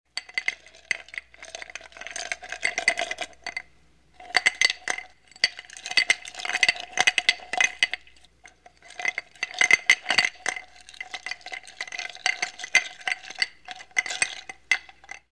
Ice Cube Glass Stir Sound
household